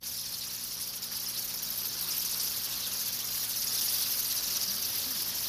Bat.mp3